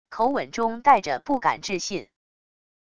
口吻中带着不敢置信wav音频